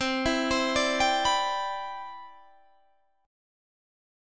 CM9 chord